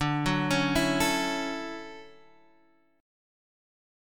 D Major 9th